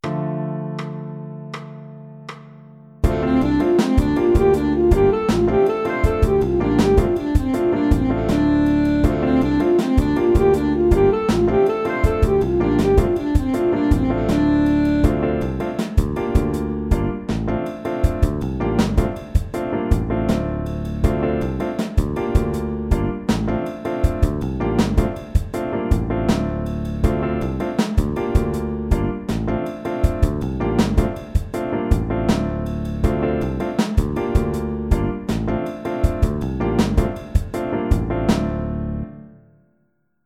Pentatonik Moll 16tel
PhraTechRegAusglPentatonikMoll16tel.mp3